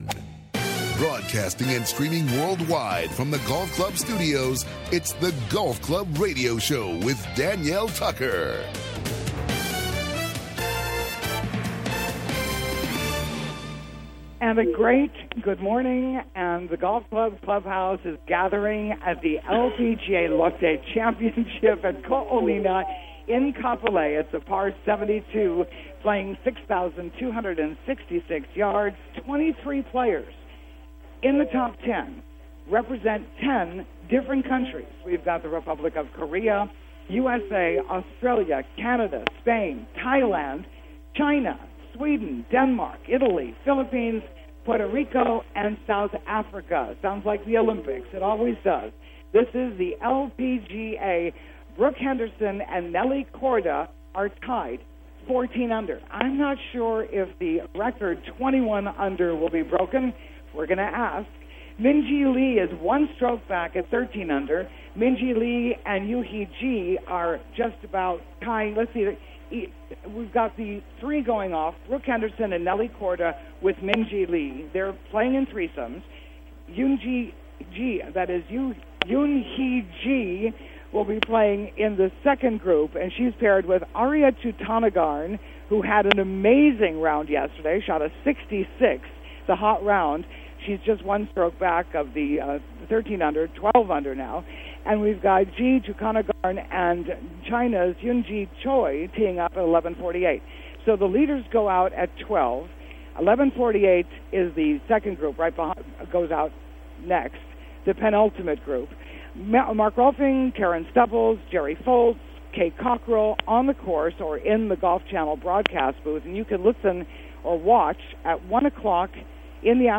Live SATURDAY MORNINGS: 7:00 AM - 8:30 AM HST
Jerry Foltz Golf Channel On Course Commentator/Analyst